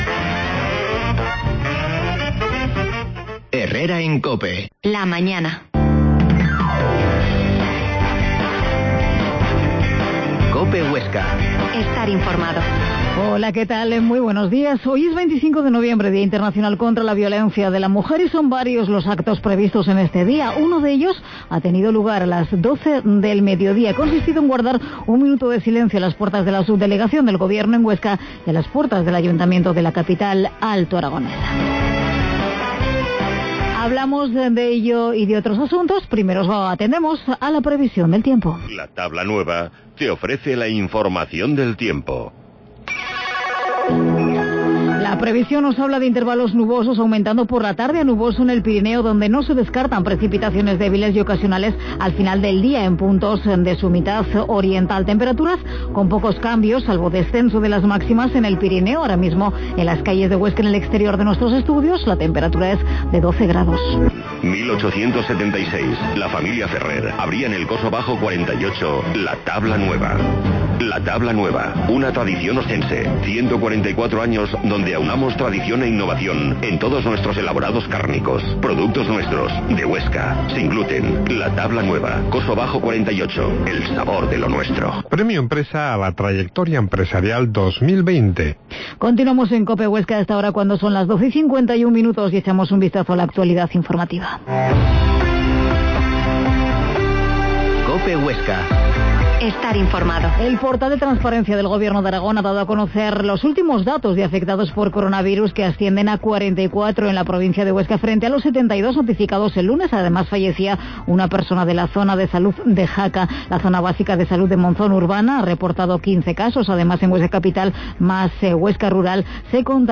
AUDIO: Ultima hora de la actualidad y entrevista a Elena Pérez en el dia de la eliminación de la violencia de género